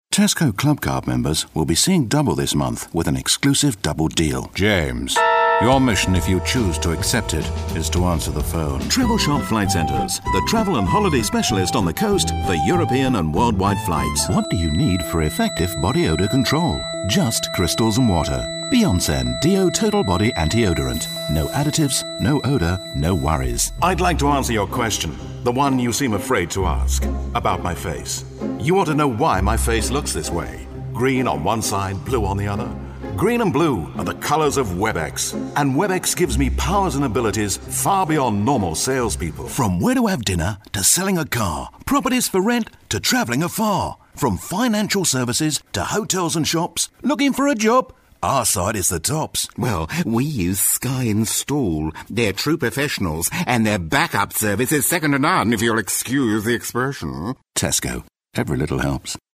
britisch
Sprechprobe: Werbung (Muttersprache):
english (uk) voice over artist.